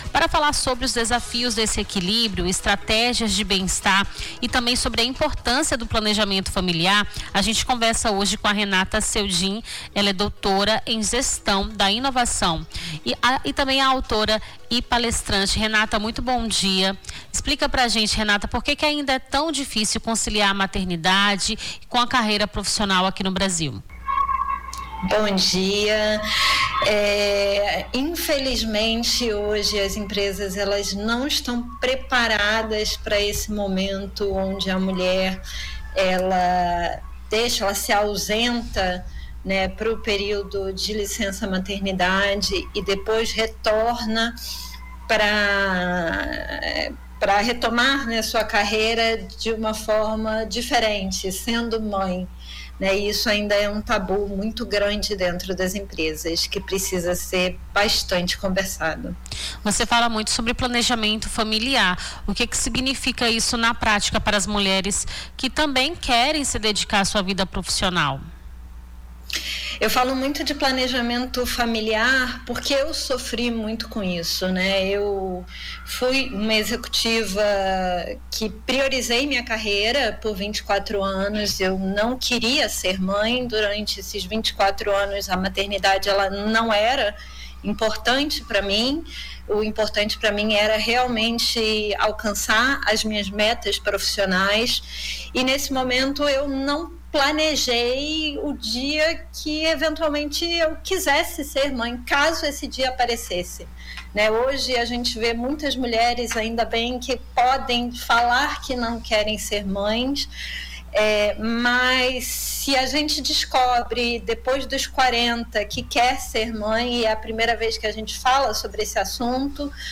Nome do Artista - CENSURA - ENTREVISTA (MATERNIDADE E MERCADO DE TRABALHO) 01-10-25.mp3